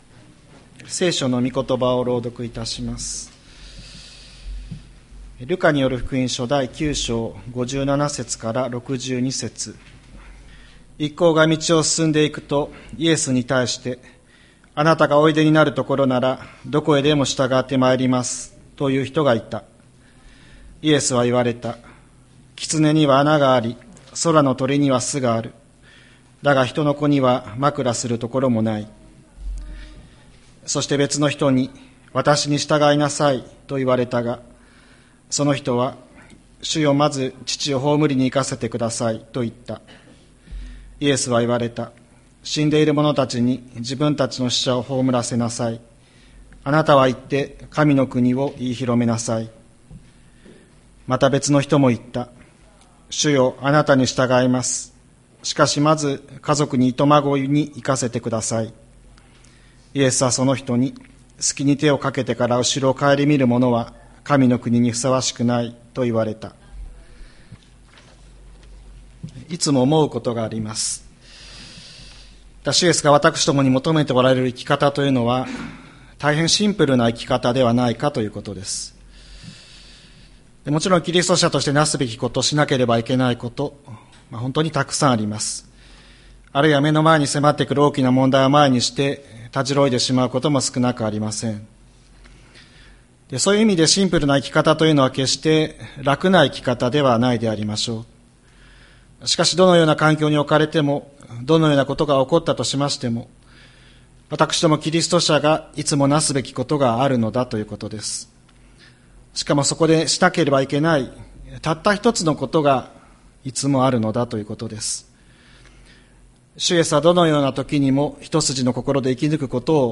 2025年07月13日朝の礼拝「わたしに従いなさい」吹田市千里山のキリスト教会
千里山教会 2025年07月13日の礼拝メッセージ。